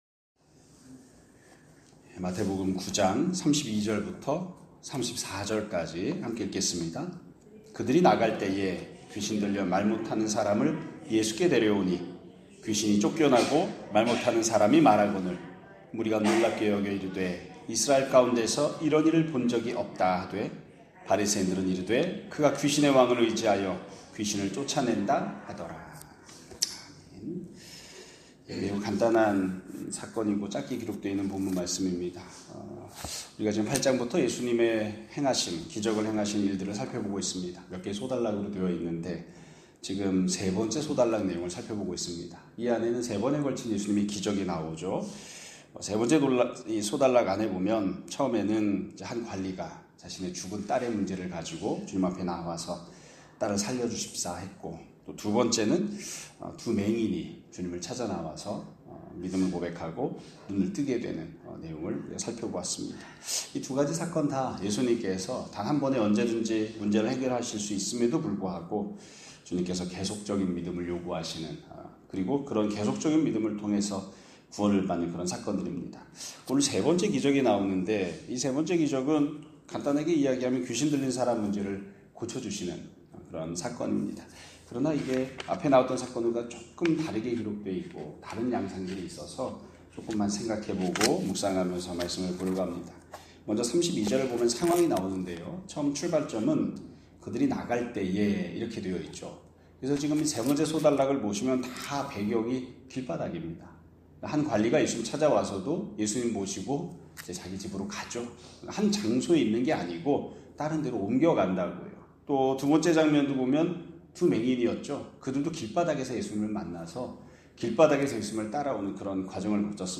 2025년 7월 25일 (금요일) <아침예배> 설교입니다.